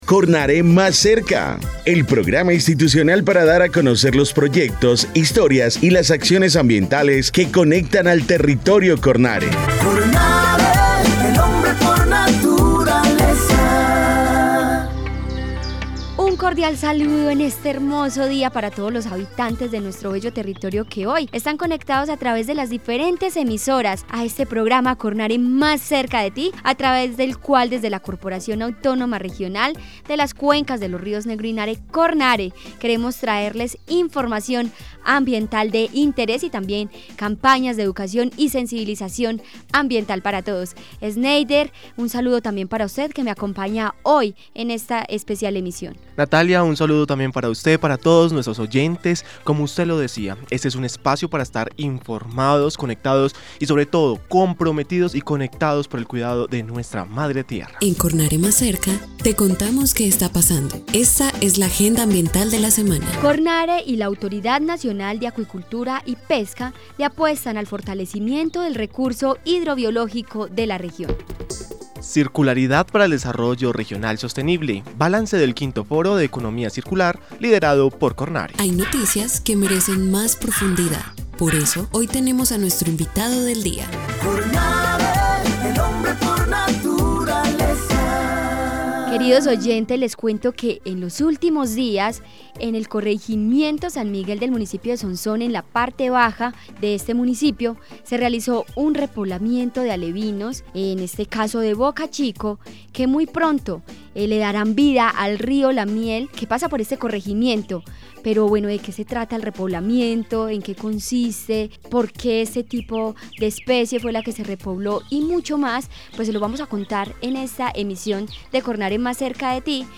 Programa de radio 2025